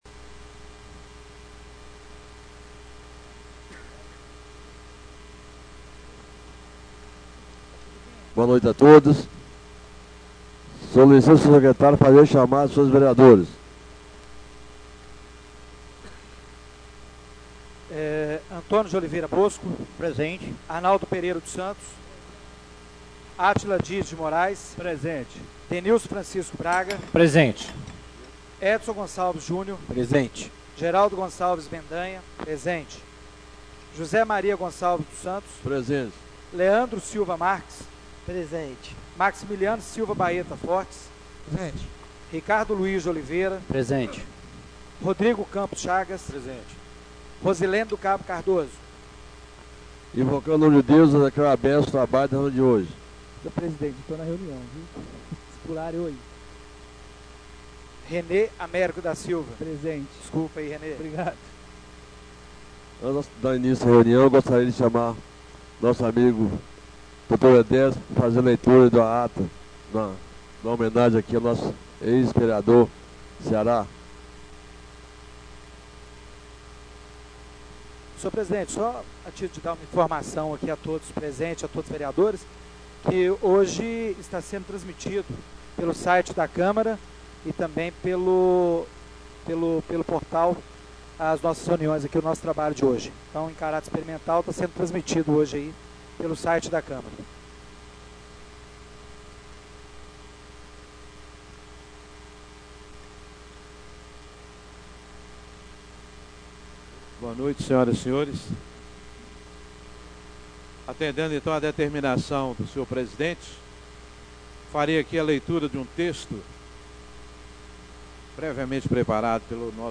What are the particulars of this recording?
Reunião Ordinária do dia 03/04/2017